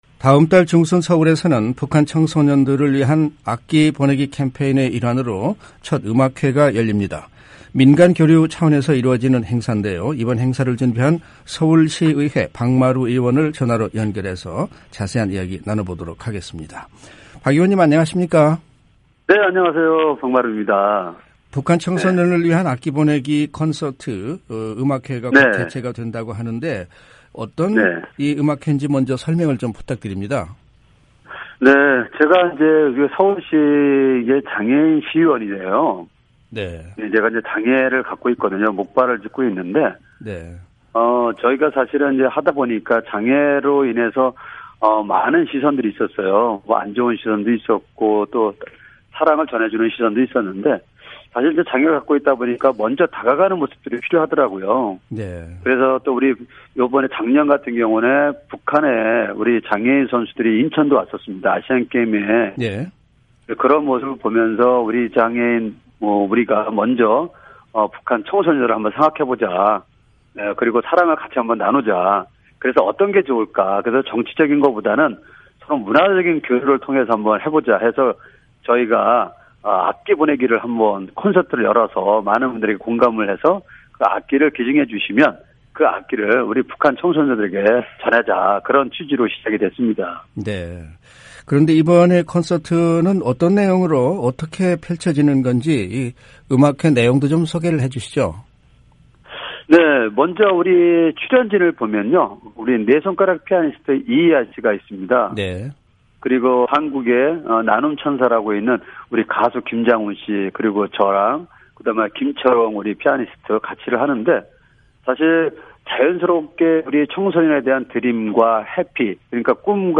이번 행사를 준비한 서울시의회 박마루 의원을 전화로 연결해 자세한 이야기 나눠보겠습니다.
[인터뷰 오디오 듣기] 박마루 서울시의회 의원